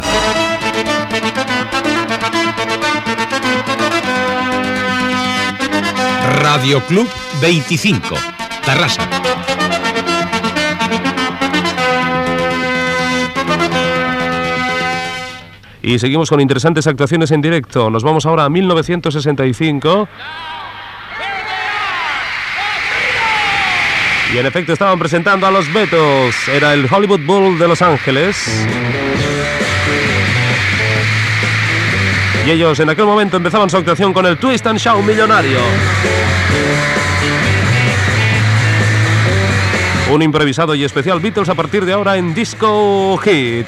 Indicatiu de l'emissora i presentació d'un tema musical dels Beatles.
Musical
FM